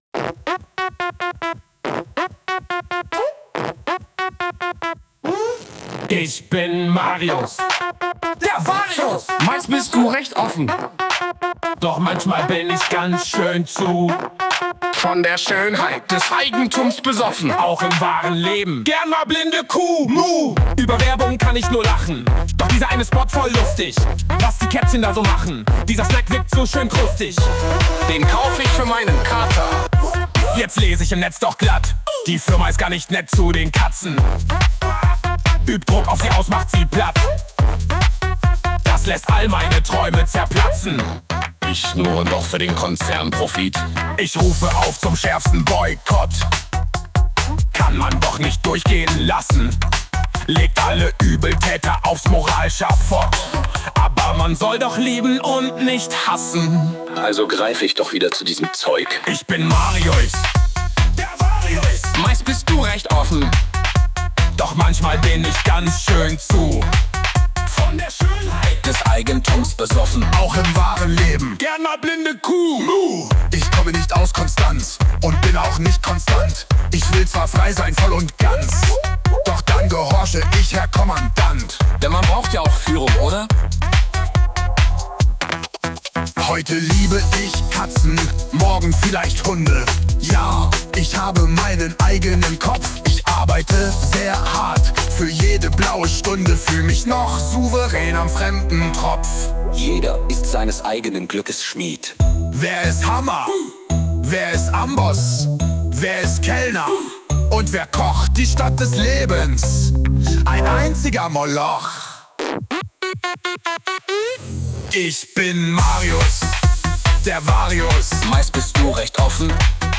Version 3: Diese stellt sicher die größte Herausforderung dar – ziemlich abgedreht, nichts für bürgerliche Hörgewohnheiten.
Marius-8-exp-schraeg.wav